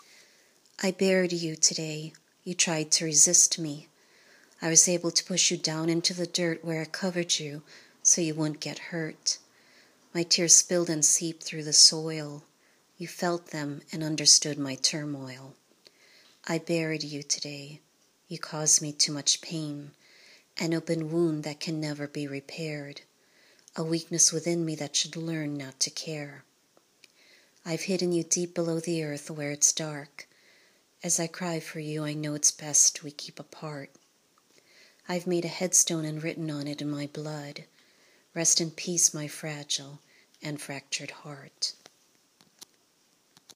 Your poem has a potent and lyrical rhythm! The audio is also clear…did you record your voice in the closet again?